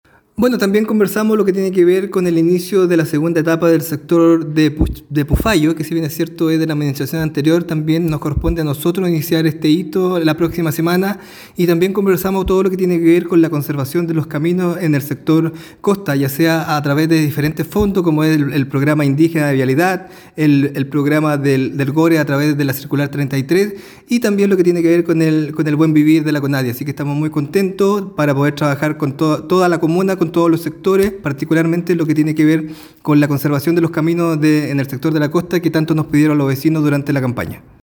El alcalde Carrillo destacó que además de estos proyectos, se revisaron los avances de la segunda etapa del asfaltado en el sector de Pufayo, una obra heredada de la administración anterior, cuyo hito inicial está programado para la próxima semana.